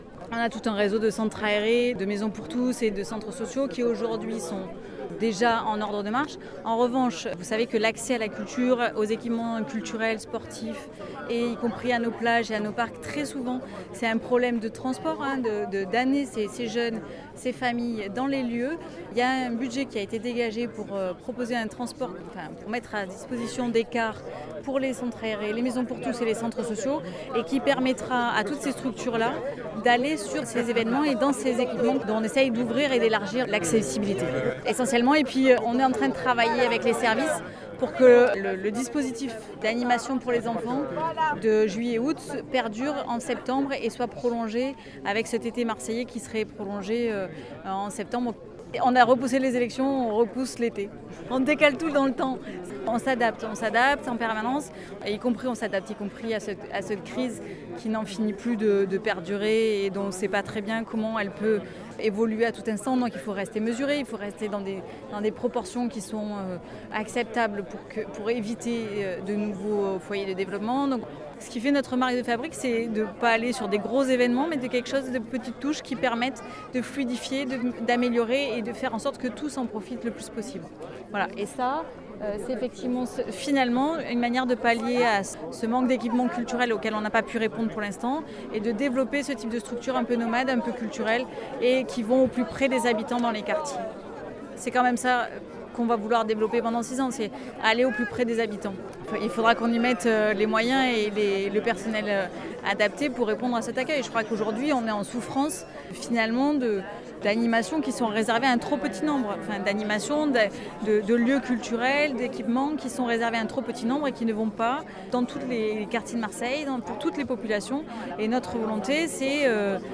Et cet été qui prend place du 1er août au 23 septembre 2020 vient d’être lancé ce 28 juillet par la maire de Marseille Michèle Rubirola accompagnée par nombre de ses adjoints et un public venu en nombre pour saluer l’initiative…